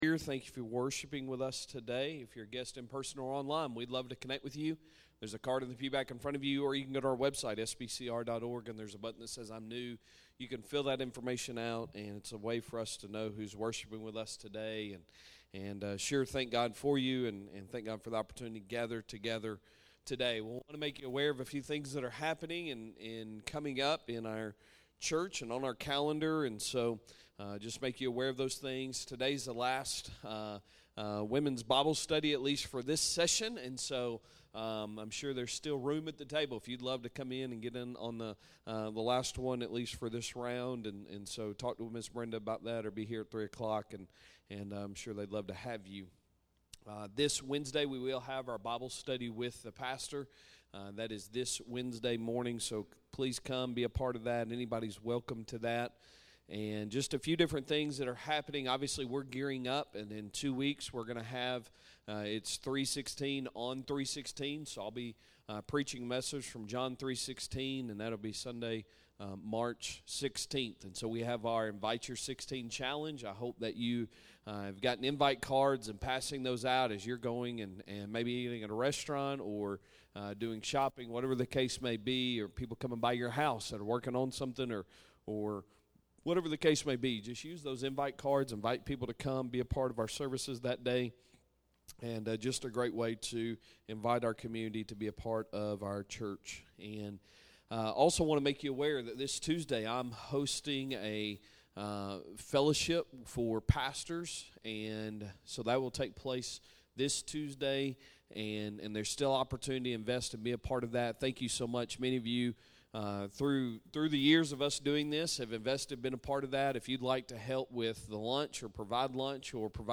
Sunday Morning Sermon March 2, 2025